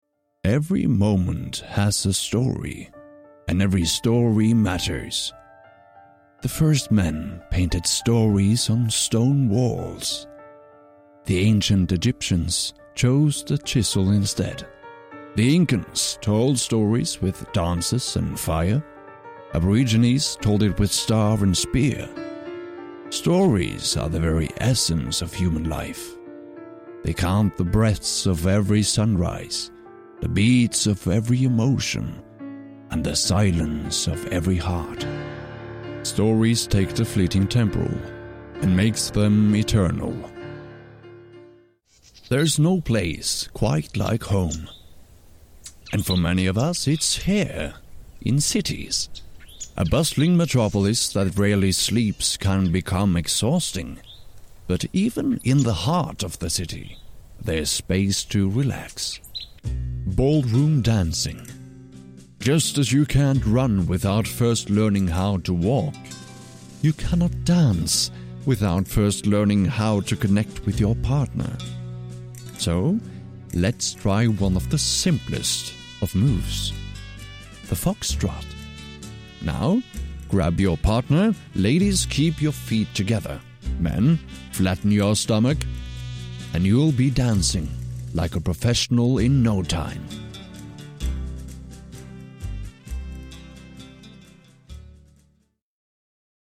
Demonstração Comercial
Mic: Shure SM7B + Trtion Audio Fethead Filter
BarítonoProfundoBaixo